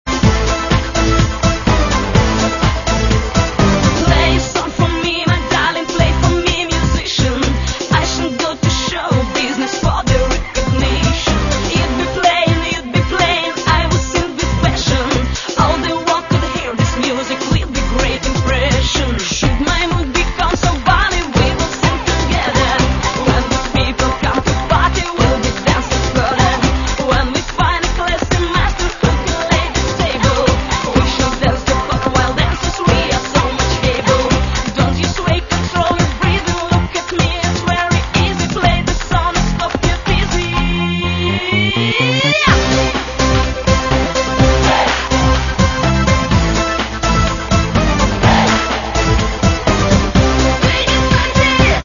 Каталог -> Поп (Легка) -> Етно-поп